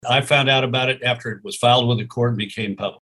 In an interview with KMBC-TV in Kansas City, Rove denies any advance knowledge of former Missouri First Lady Sheena Greitens alleging her ex-husband abused her and their children.